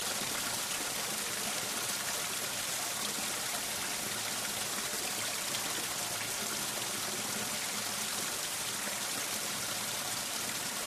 auWaterfallSplash.wav